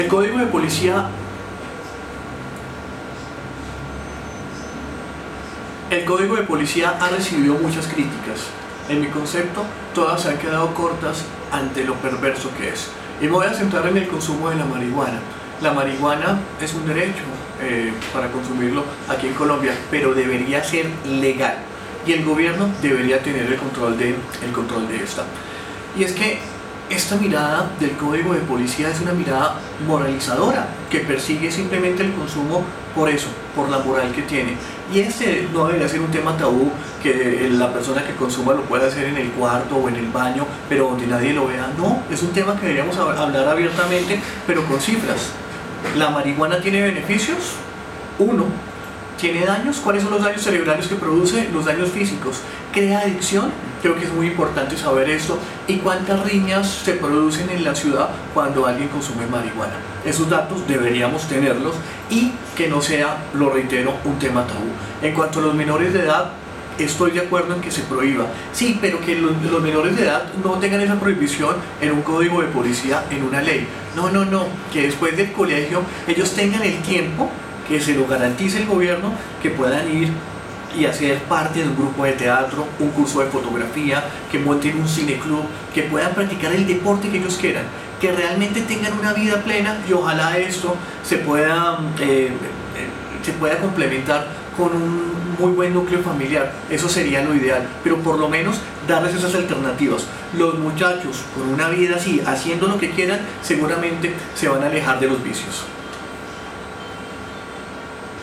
Columna 2017. Informe radial:
Programas de radio